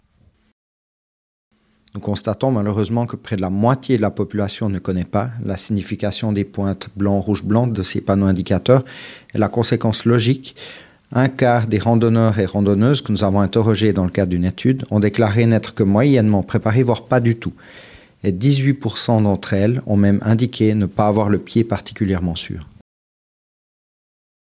porte-parole